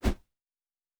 pgs/Assets/Audio/Fantasy Interface Sounds/Whoosh 10.wav at master
Whoosh 10.wav